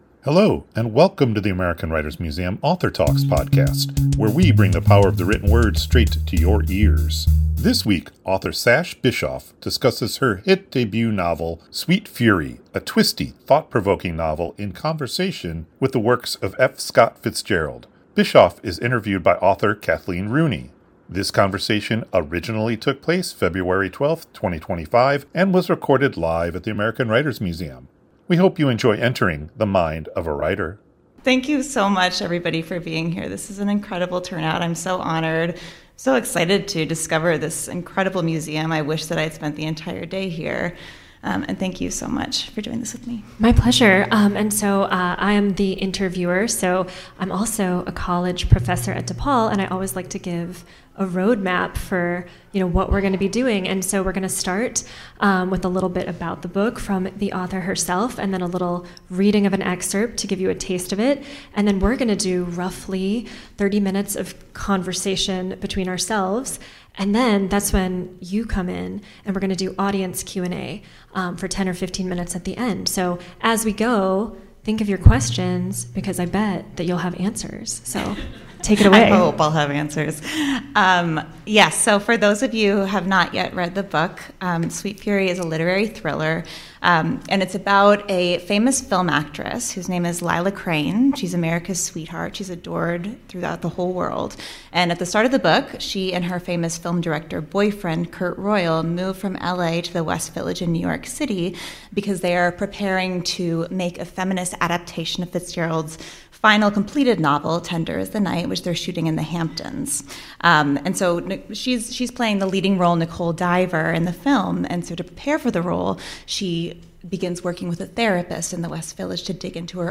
This conversation originally took place February 12, 2025 and was recorded live at the American Writers Museum.